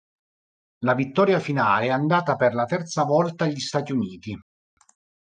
Read more Noun Verb Frequency A1 Hyphenated as an‧dà‧ta Pronounced as (IPA) /anˈda.ta/ Etymology Deverbal formed with the feminine past participle of andare.